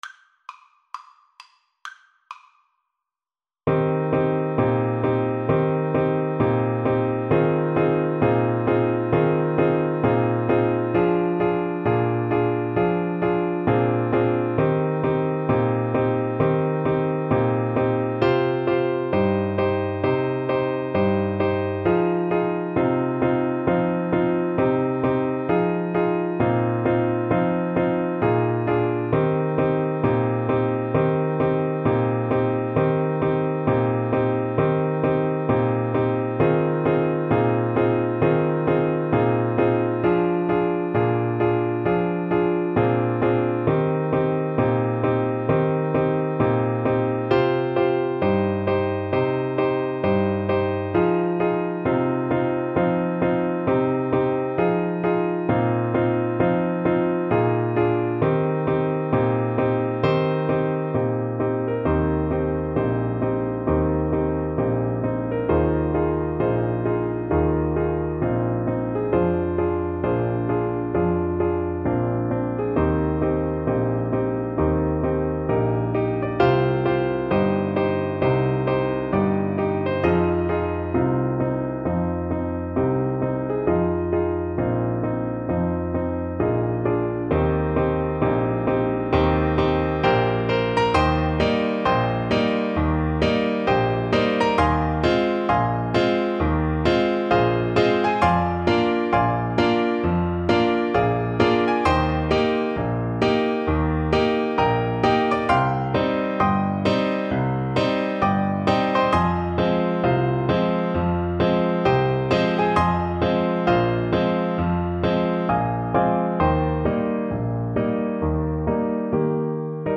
Blues Tempo (=66)
Jazz (View more Jazz Soprano Saxophone Music)